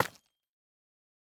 Minecraft Version Minecraft Version 21w11a Latest Release | Latest Snapshot 21w11a / assets / minecraft / sounds / block / calcite / break2.ogg Compare With Compare With Latest Release | Latest Snapshot